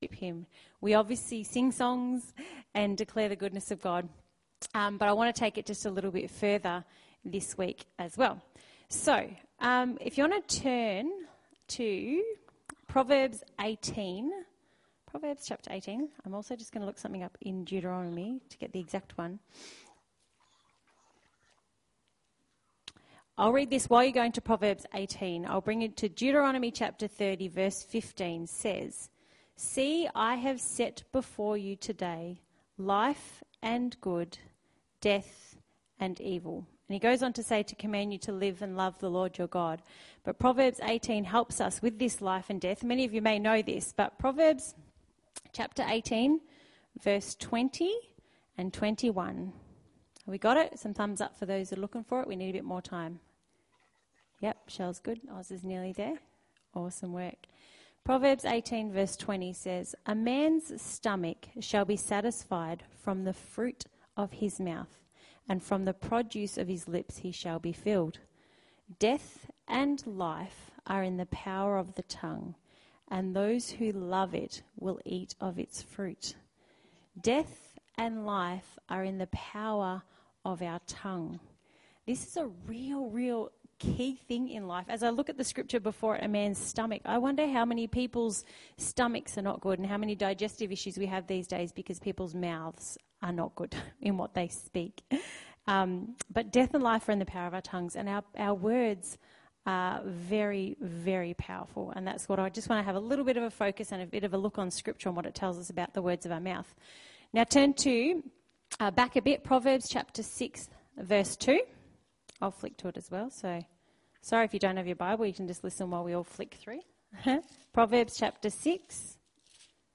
Sunday Service 30/08/20 (Audio only)